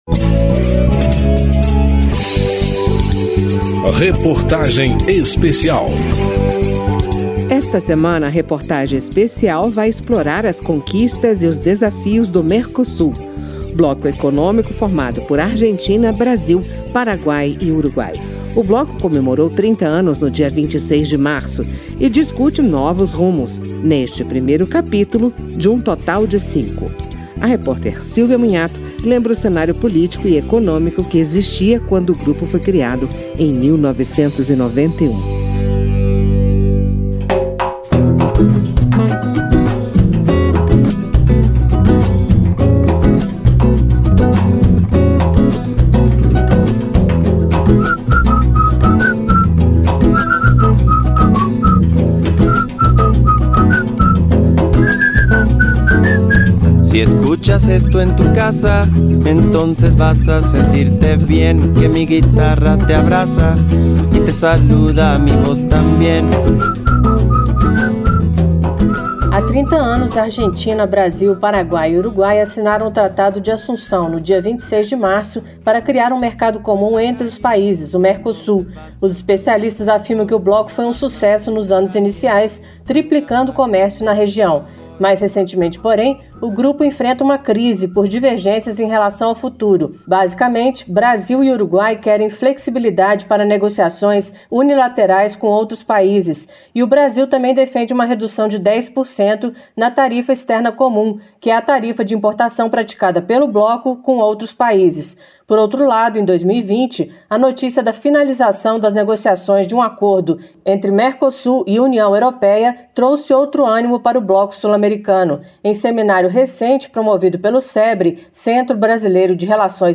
Reportagem Especial
Entrevistas neste capítulo: senador Fernando Collor (PROS-AL); embaixador Rubens Ricúpero; ministro da Economia, Paulo Guedes.